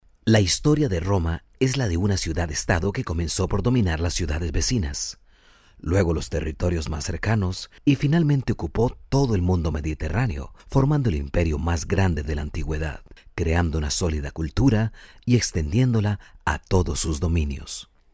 Costa Rican voice over
Costa Rican female voice overs Cosa Rican male voice talents